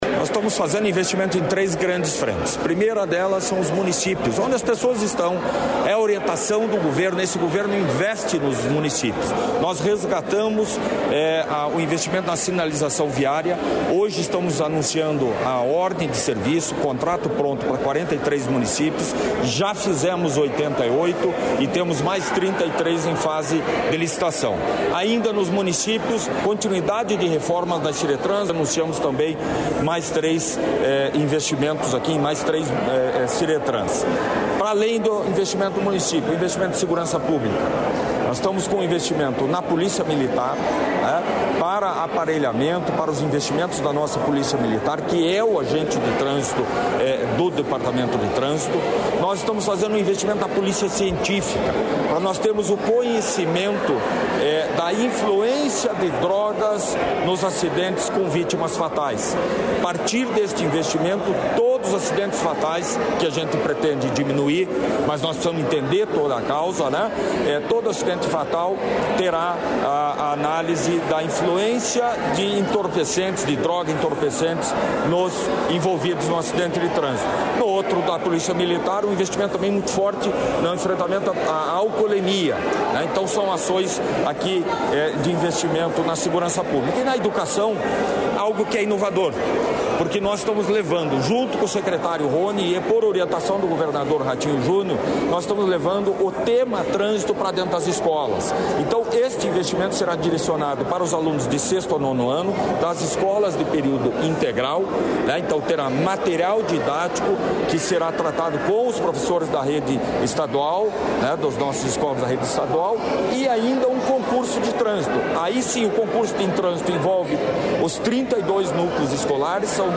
Sonora do diretor-geral do Detran-PR, Adriano Furtado, sobre as ações de segurança no trânsito